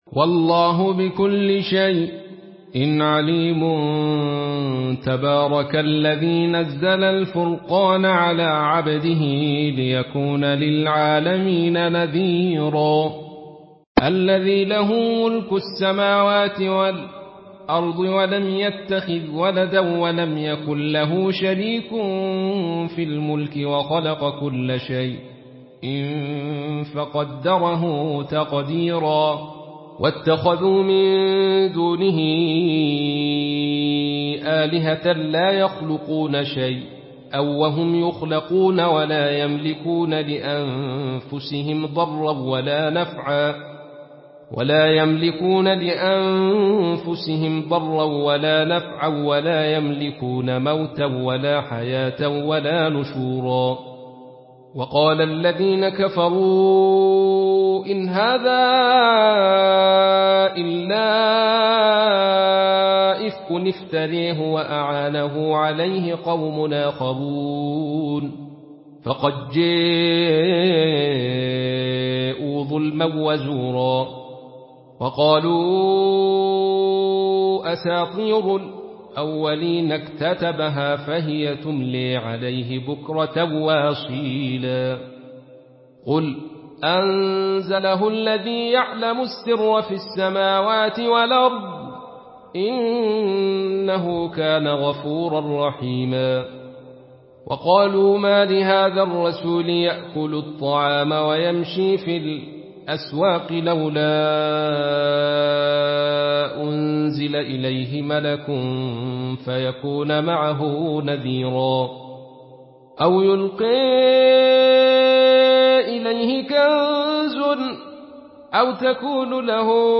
Surah Al-Furqan MP3 in the Voice of Abdul Rashid Sufi in Khalaf Narration
Listen and download the full recitation in MP3 format via direct and fast links in multiple qualities to your mobile phone.